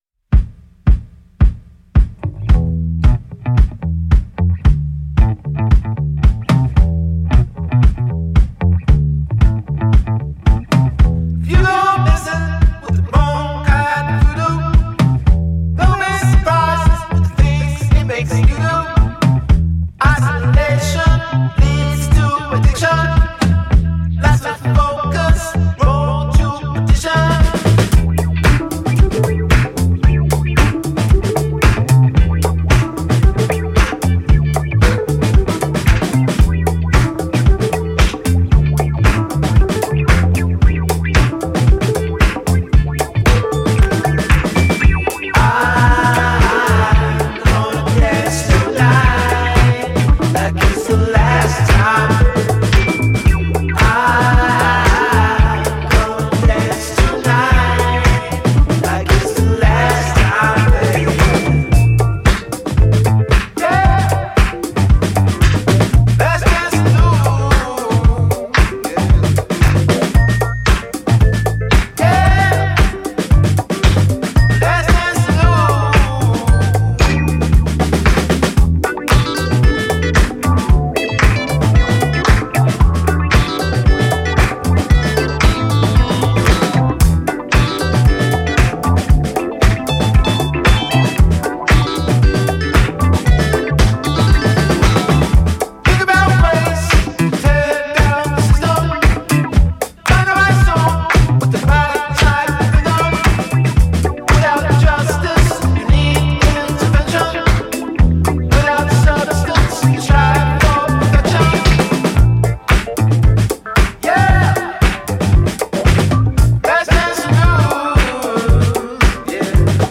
Head nod inducing, too slow to disco affair